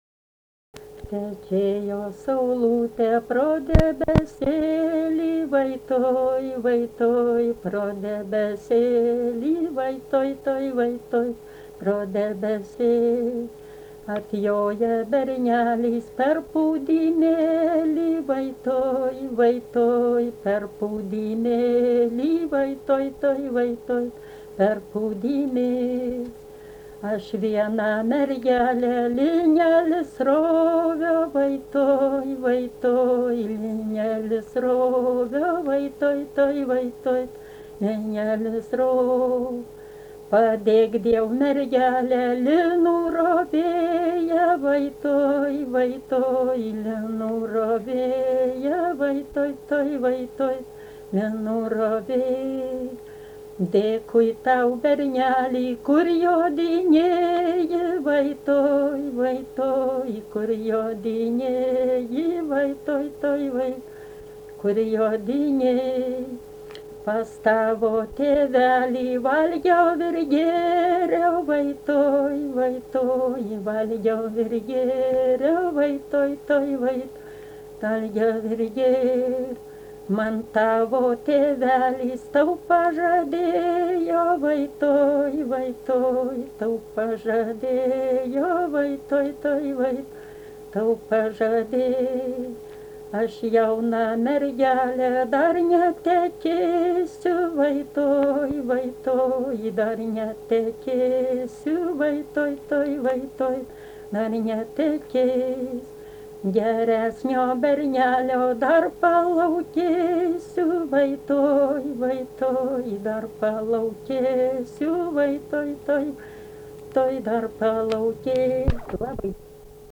Dalykas, tema daina
Erdvinė aprėptis Čypėnai
Atlikimo pubūdis vokalinis